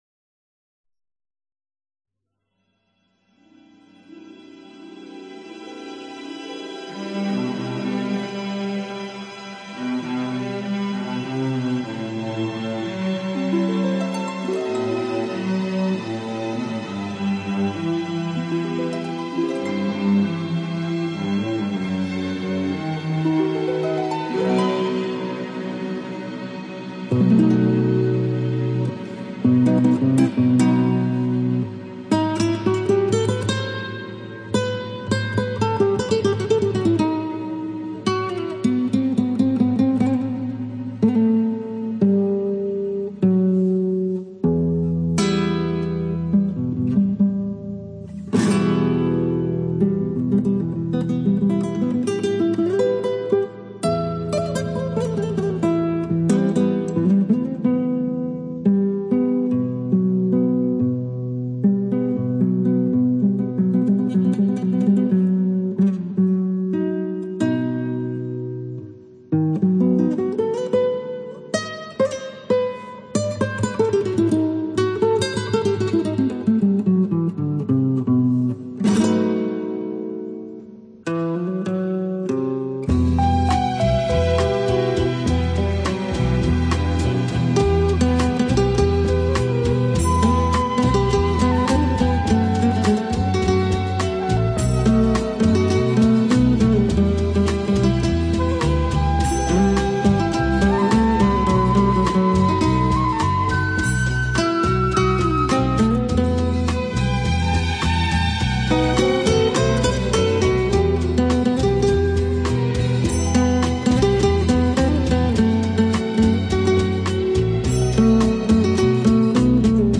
Жанр: Easy Listening, Acoustic Guitar, Instrumental Pop